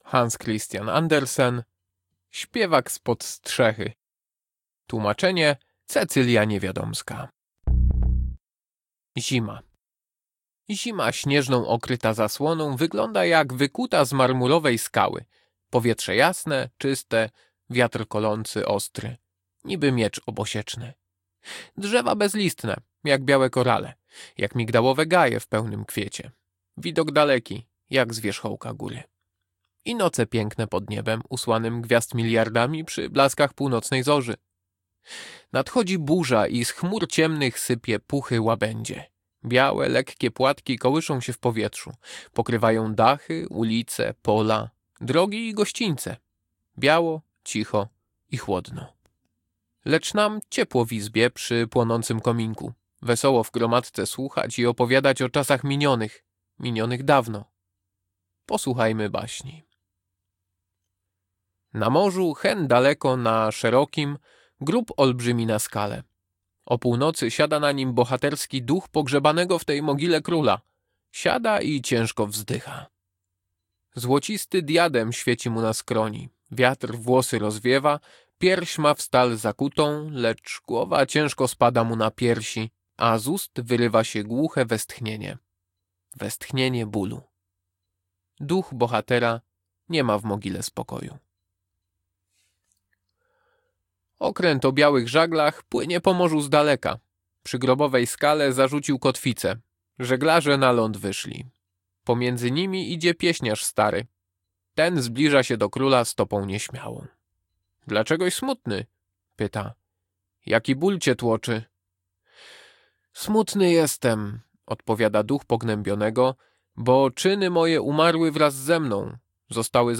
Baśń
Audiobook